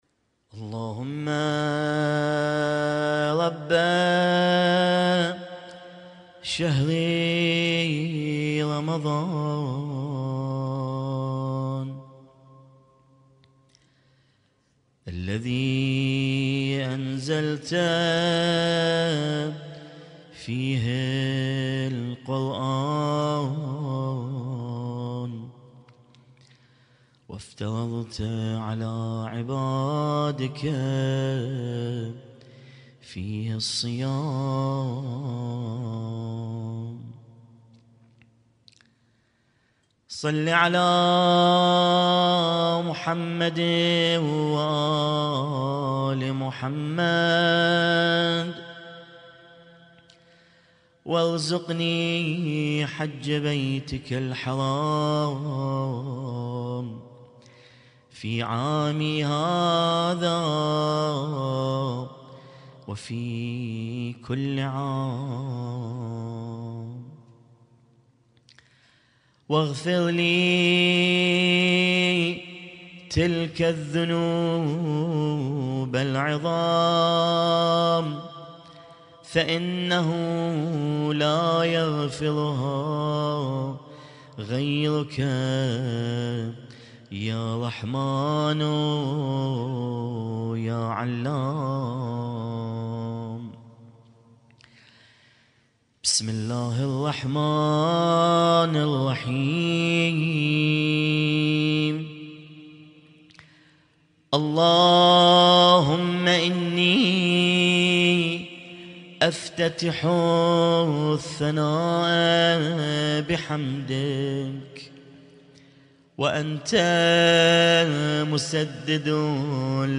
اسم التصنيف: المـكتبة الصــوتيه >> الادعية >> دعاء الافتتاح